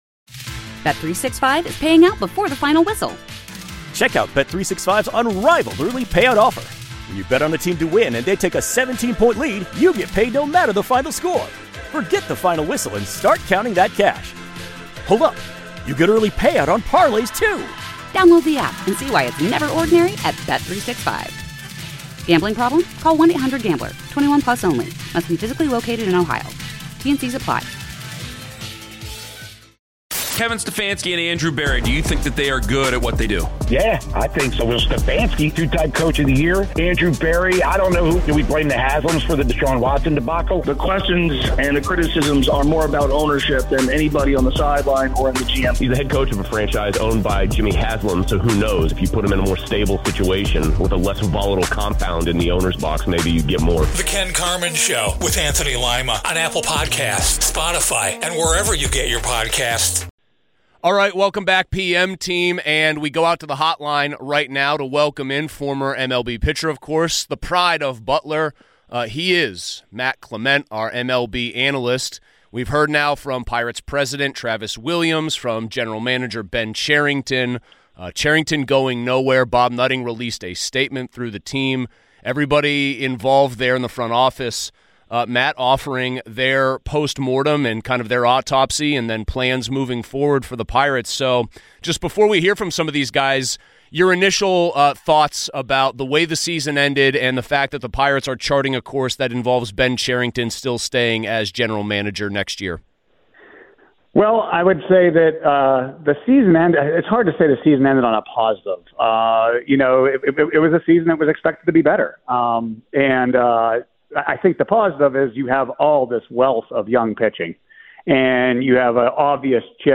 Former MLB pitcher Matt Clement joined the show. Matt reacted to the end of the Pirates season and some of the changes they have made.